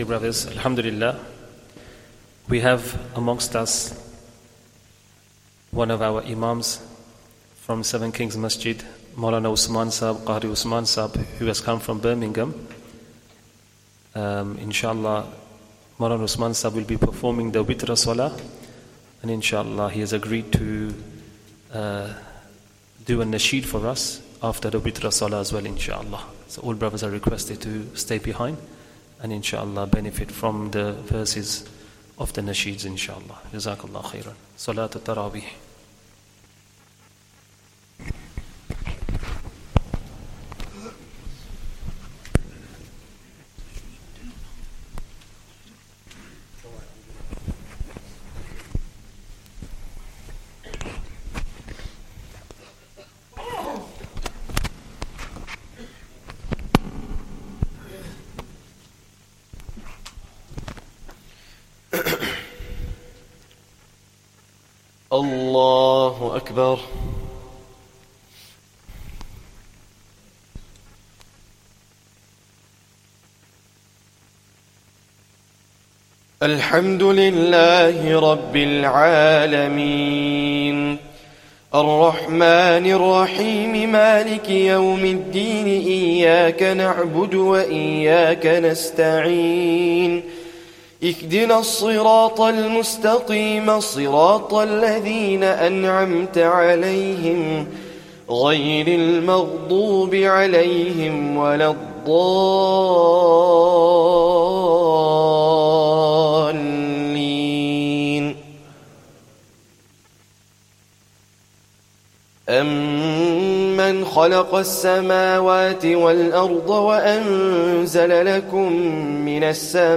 Taraweeh Prayer 18th Ramadhan